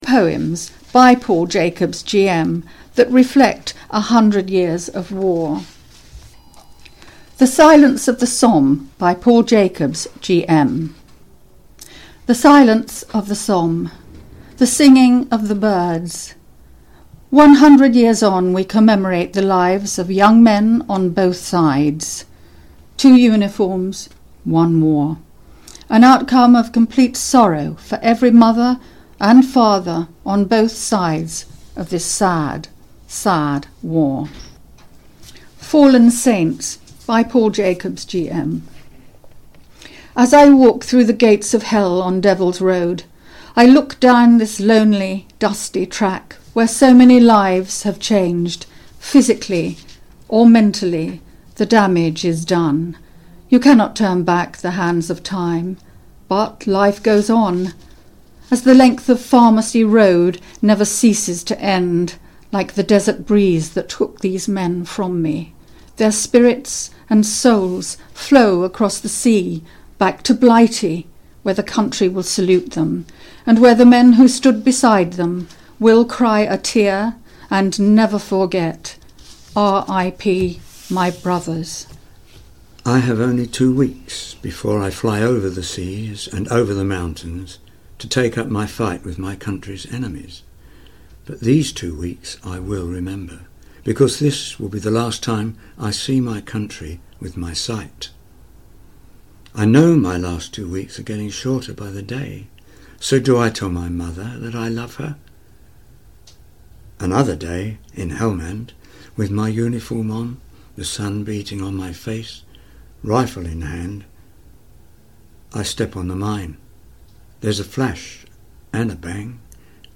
Two very moving poems